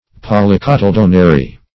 Search Result for " polycotyledonary" : The Collaborative International Dictionary of English v.0.48: Polycotyledonary \Pol`y*cot`y*led"on*a*ry\, a. [Poly- + cotyledonary.]